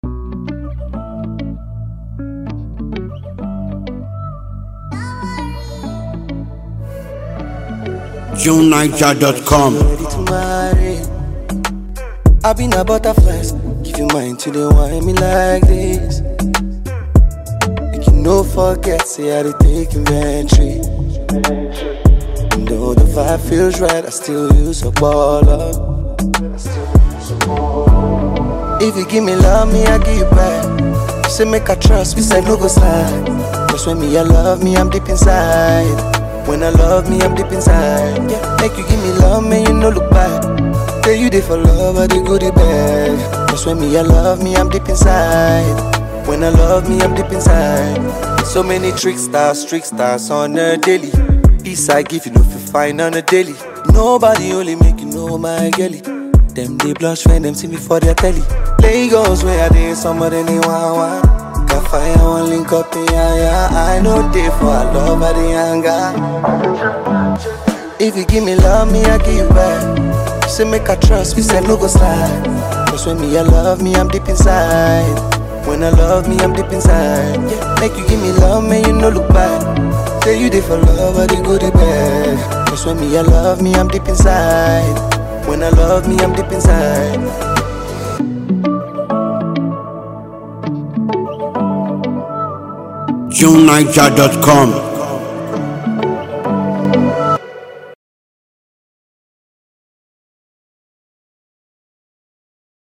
unpolished vocals
catchy hit